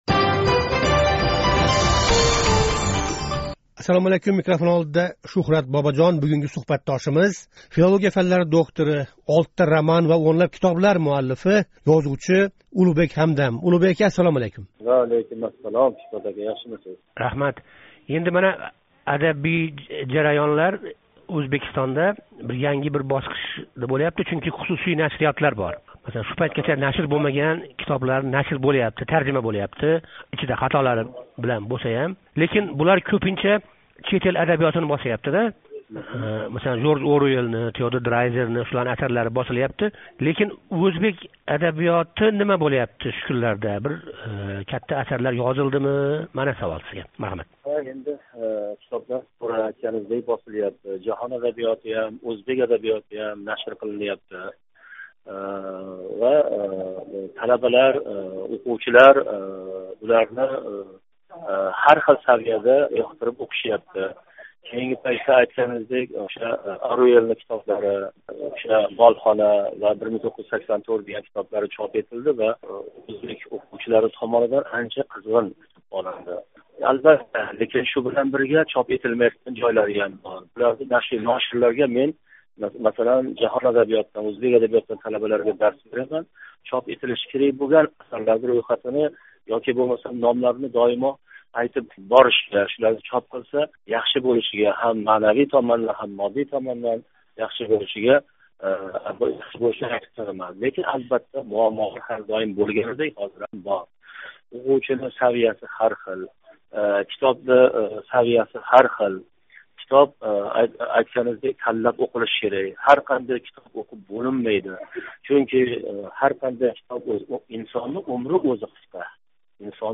Ëзувчи Улуғбек Ҳамдам билан суҳбат